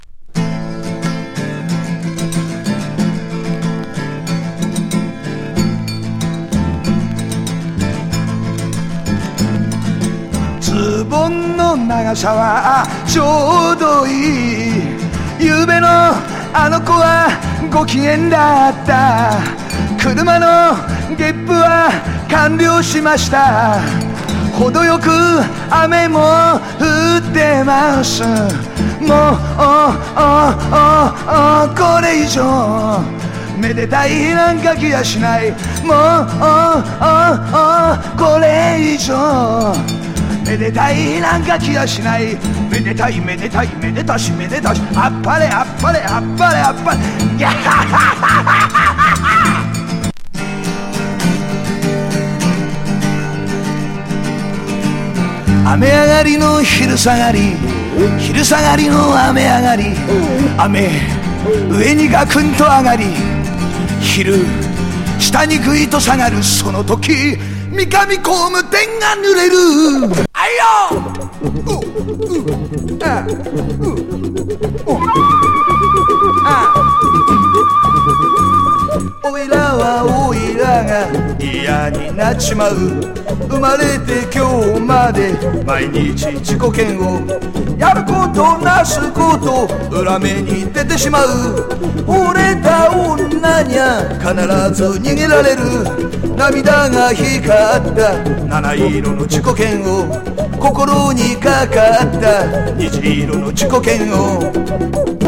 小市民チンドン囃子
濃厚サンバ